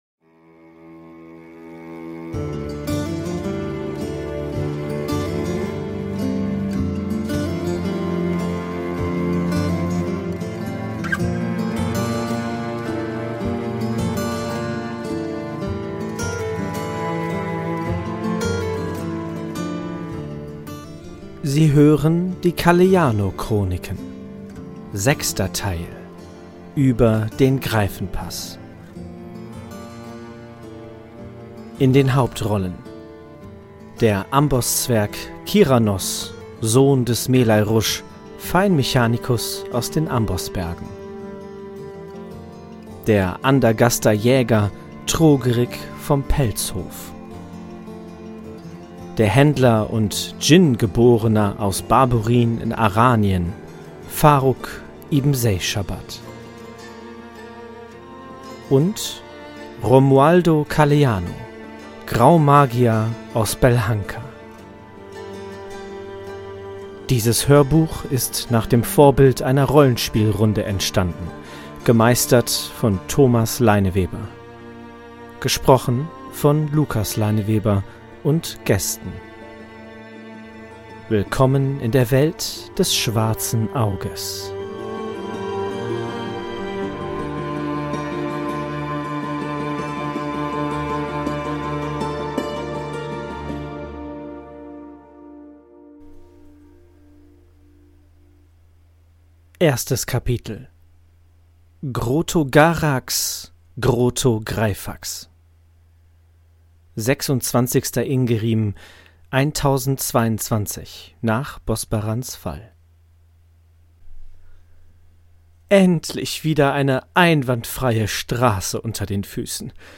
Die Calleano-Chroniken – Ein Hörbuch aus der Welt des schwarzen Auges